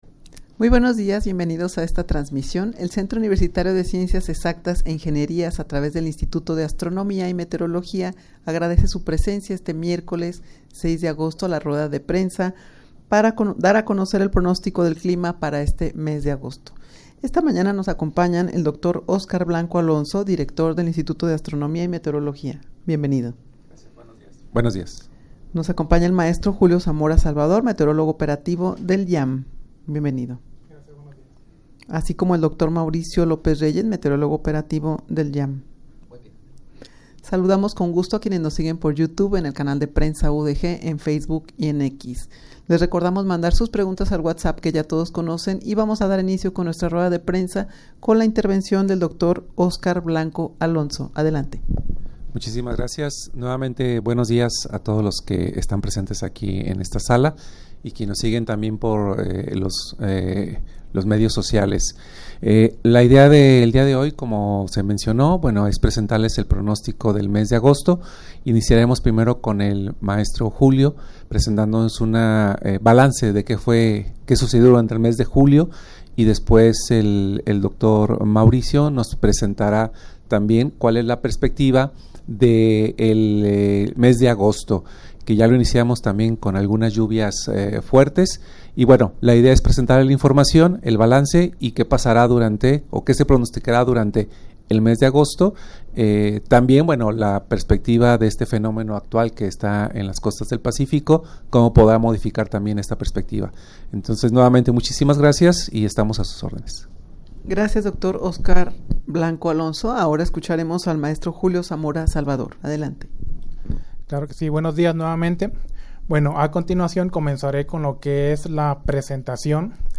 Audio de la Rueda de Prensa
rueda-de-prensa-para-dar-a-conocer-el-pronostico-del-clima-de-agosto.mp3